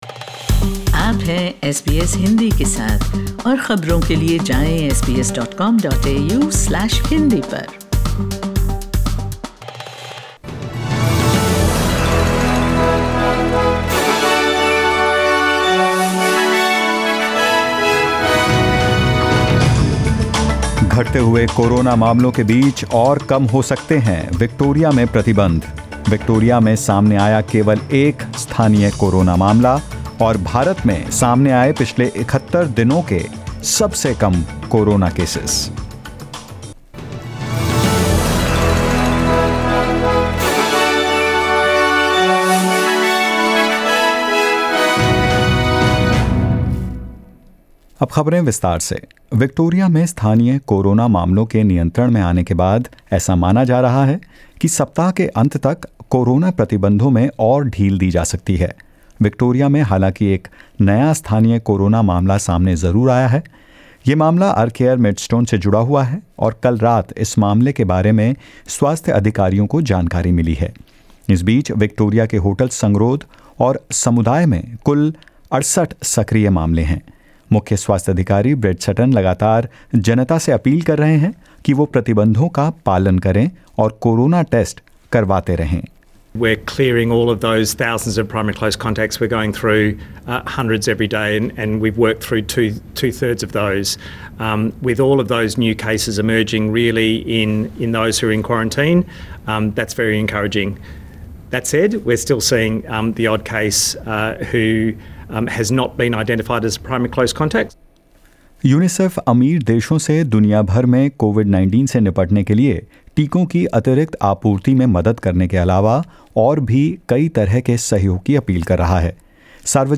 In this latest SBS Hindi News bulletin of Australia and India: Labor pushing for more action on climate change after G7 Summit talks; Unseeded tennis player Barbora Krejcikova wins first Grand Slam title at the French Open and more.